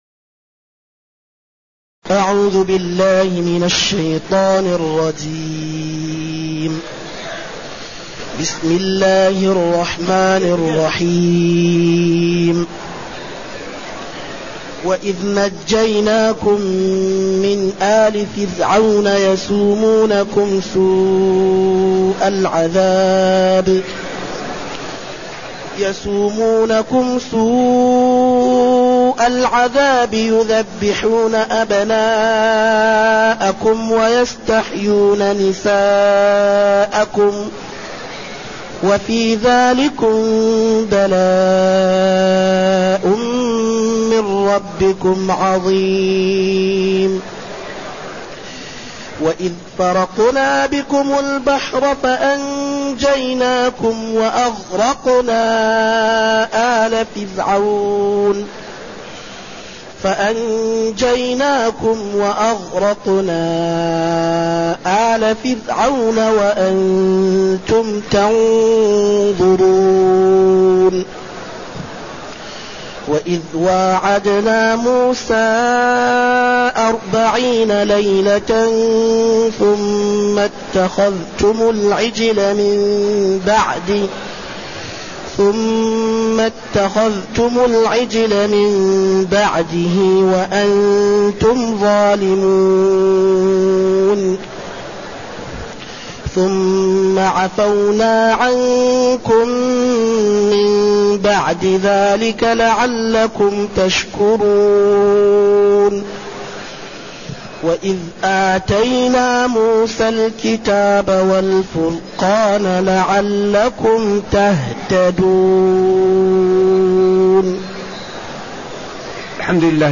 تاريخ النشر ٦ محرم ١٤٢٨ هـ المكان: المسجد النبوي الشيخ